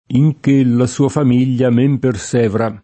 perseverare v.; persevero [perS$vero] — es. con acc. scr.: Persèvero lo sforzo mio ventenne [perS$vero lo Sf0rZo m&o vent$nne] (D’Annunzio) — un es. ant. di sincope: In che la sua famiglia men persevra [